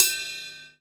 • Drum Crash Sound E Key 02.wav
Royality free crash sample tuned to the E note. Loudest frequency: 7095Hz
drum-crash-sound-e-key-02-NfJ.wav